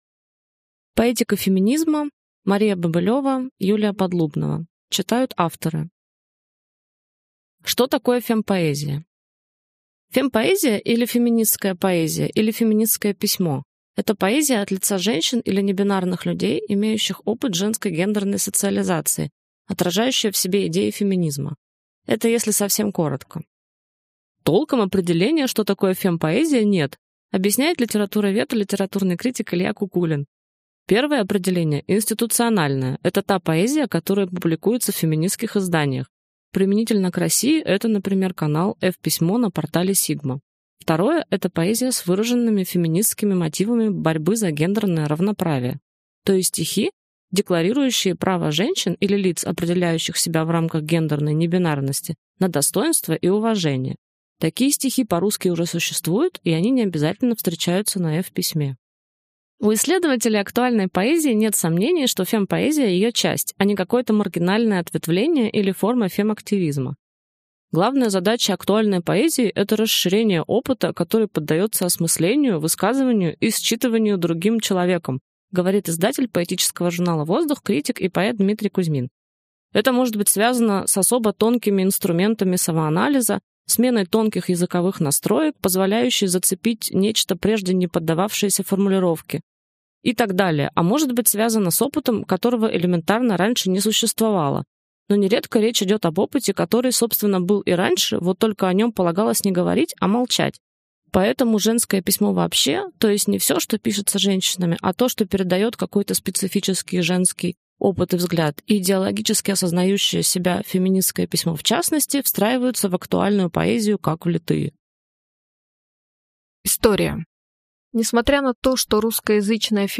Аудиокнига Поэтика феминизма | Библиотека аудиокниг